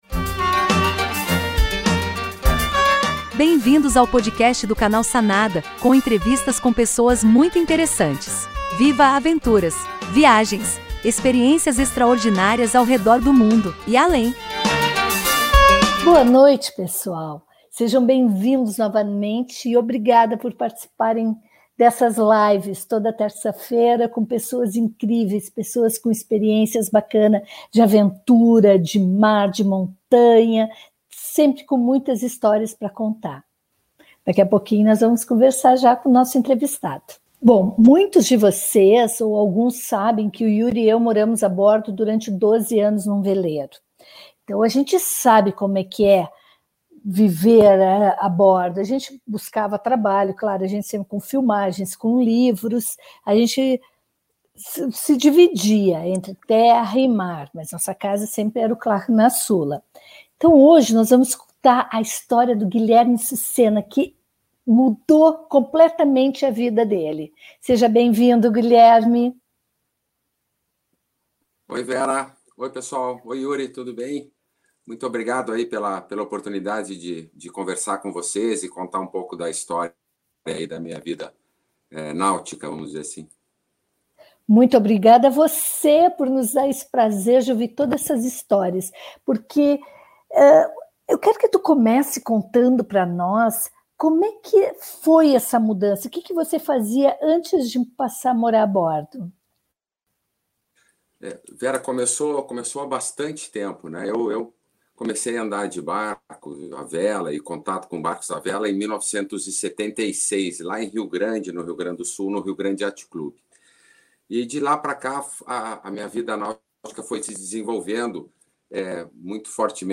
Que tal morar a bordo? Velejador, Master of Yachts Unlimited, que vive e trabalha a partir de seu veleiro oceânico. Entrevistas incríveis ao VIVO todas as terças-feiras às 19h30 no Canal Sanada do YouTube.